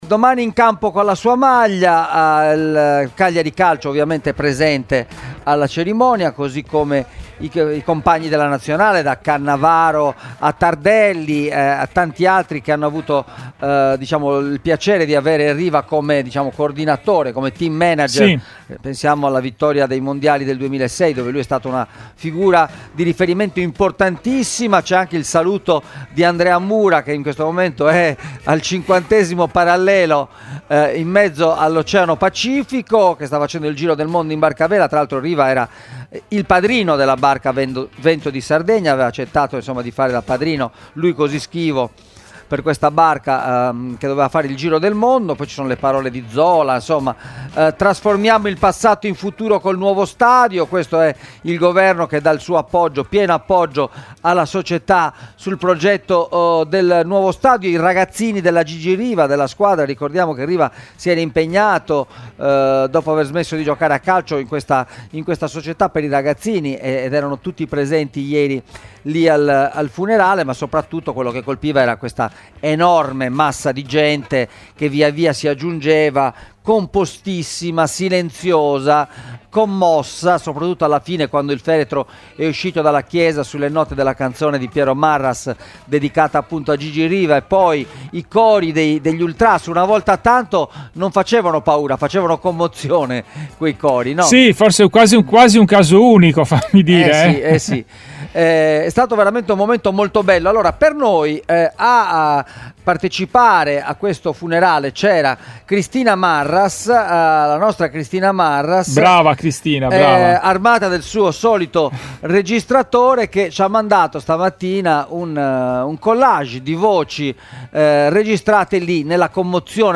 Una folla composta, silenziosa, commossa. Poi gli applausi, scroscianti, e i cori degli ultras: erano in 30.000 a Bonaria per l’ultimo saluto a Gigi Riva. Sono arrivati da tutta la Sardegna, ma anche dal continente.
ciaocampione-gigiriva-interviste.mp3